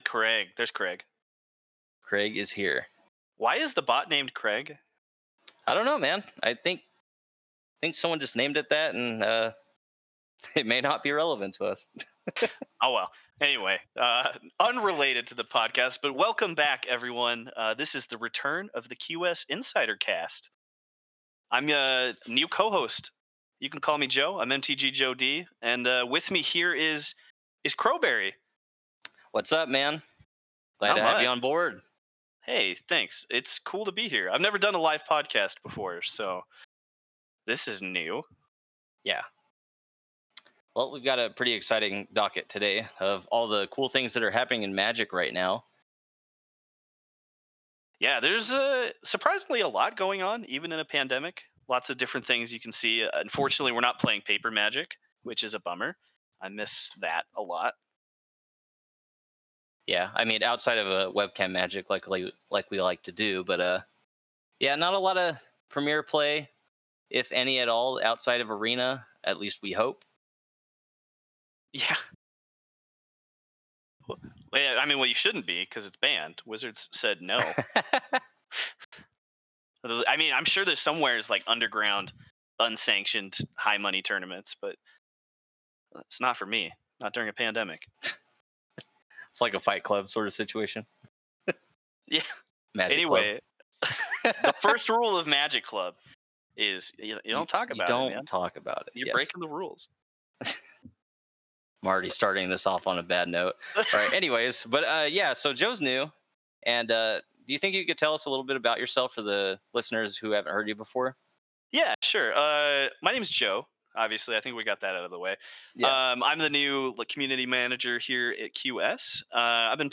This cast was originally broadcasted live to Insiders in the QS Insider Discord, September 24th, 2020.